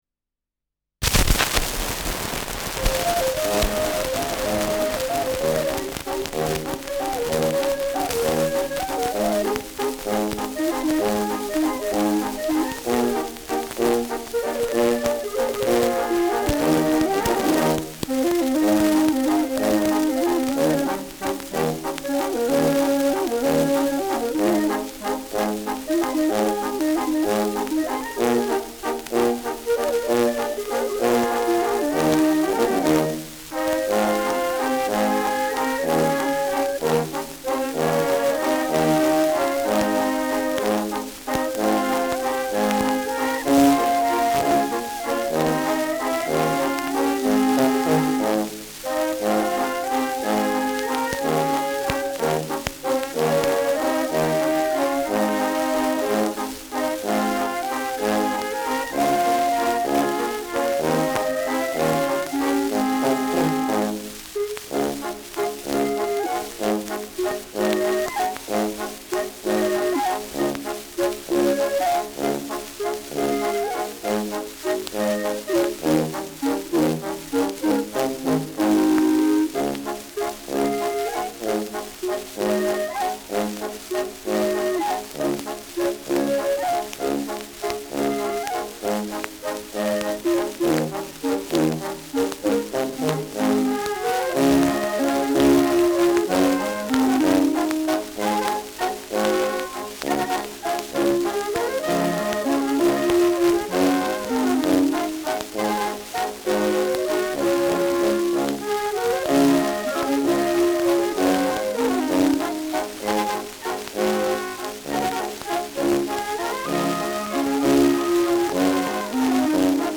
Schellackplatte
Stark verrauscht : Durchgehend leichtes stärkeres Knacken
Die gemüatlichen Münchener (Interpretation)